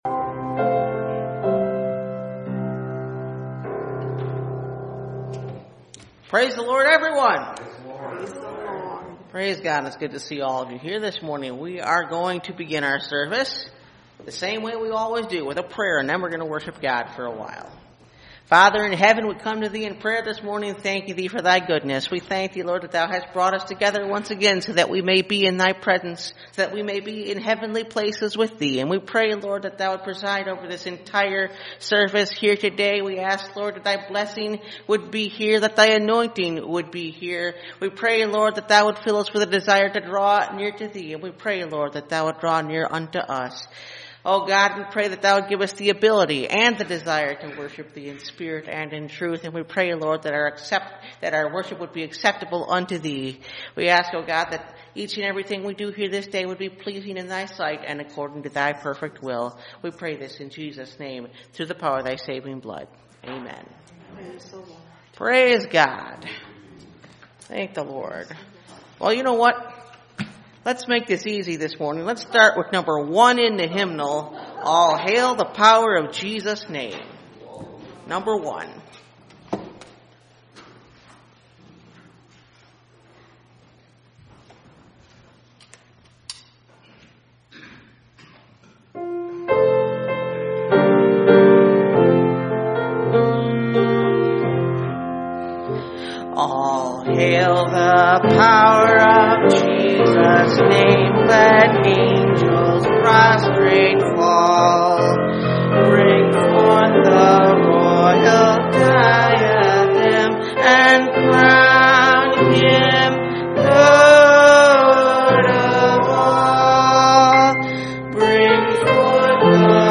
Perilous Times 2021 – Last Trumpet Ministries – Truth Tabernacle – Sermon Library